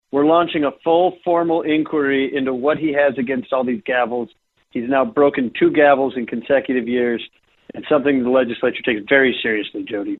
District 24 Representative and House Republican Party Leader Will Mortenson of Pierre continued with the humor of the situation. Being a lawyer by trade, Mortenson says he knows exactly what to do.